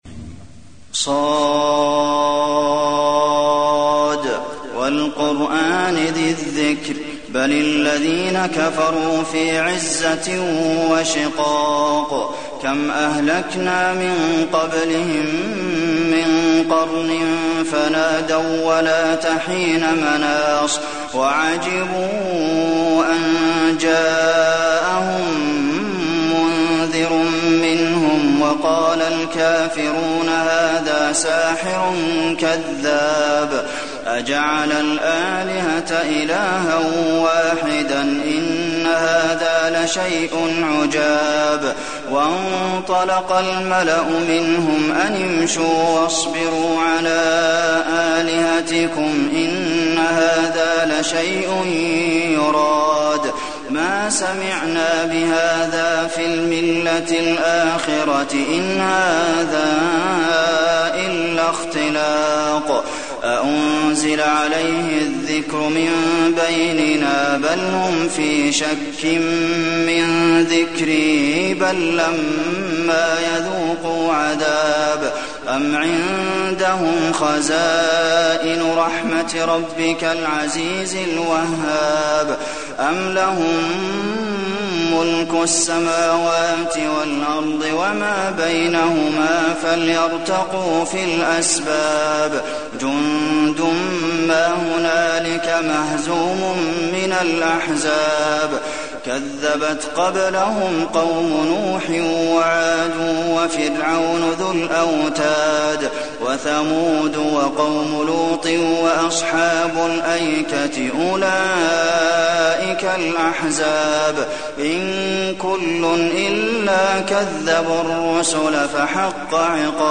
المكان: المسجد النبوي ص